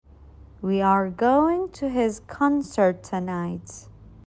تلفظ با سرعت‌های مختلف